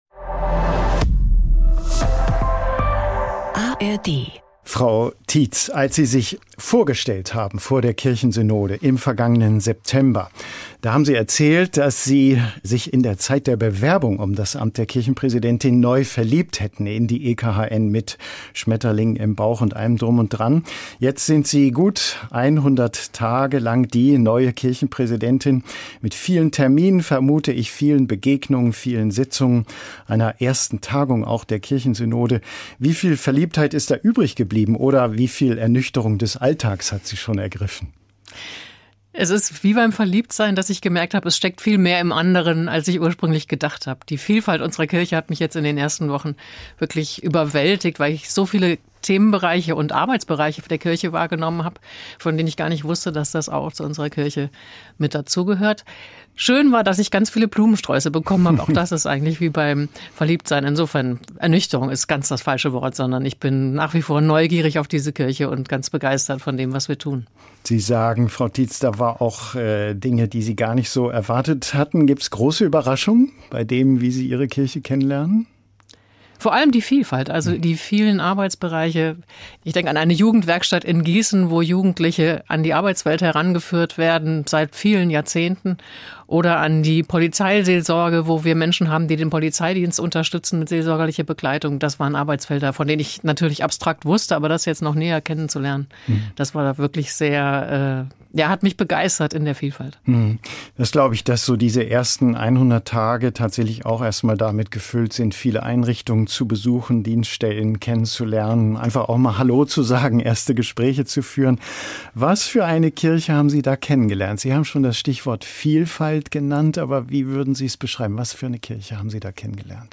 Reportagen aus der Welt der Kirchen und Religionen. Lebensfragen, Ethik, Theologie.